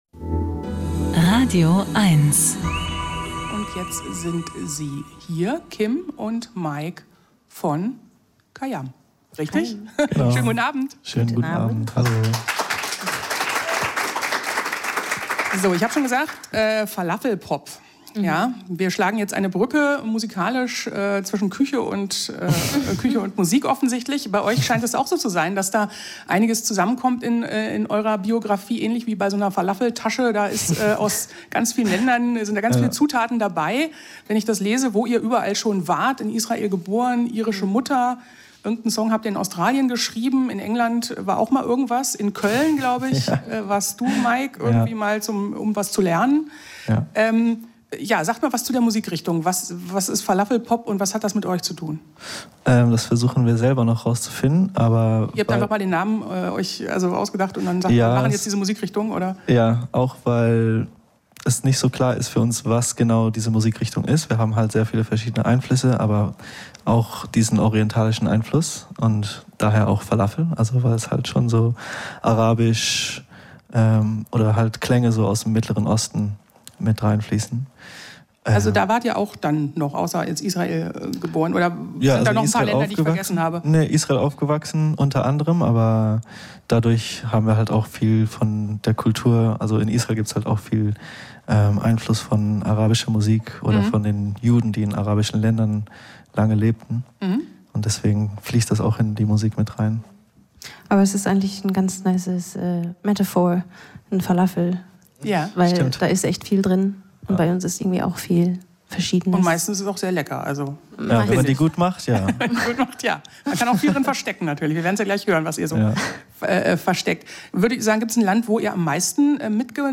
Musik-Interviews
Die besten Musikerinnen und Musiker im Studio oder am Telefon gibt es hier als Podcast zum Nachhören.